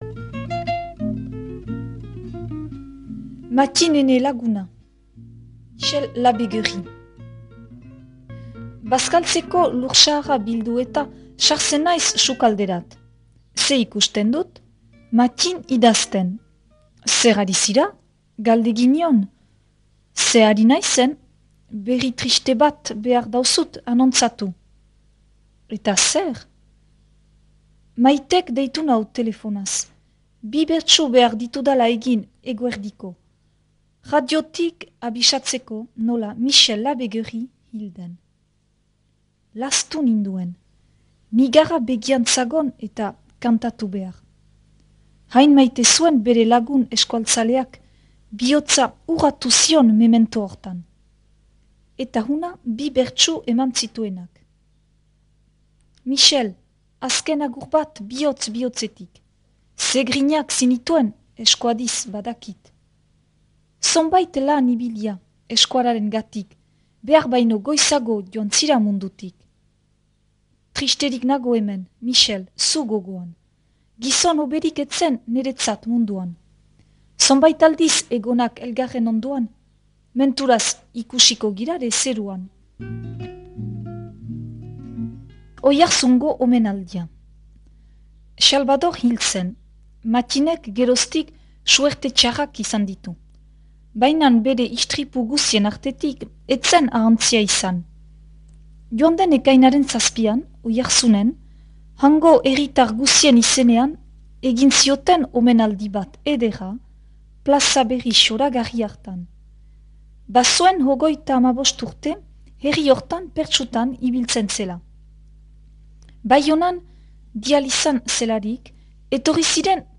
Gure artxiboetarik atera sail hau, Ahetzeko Mattin Trecu (1916-1981) bertsolariari eskainia. Mila bederatziehun ta laurogoi ta bian Maddi Trecu bere emazteak "Mattin, ene laguna" izenburuko liburutik hartu hogoi ta hamar atal edo zati dira.
proposatu irakurketa da.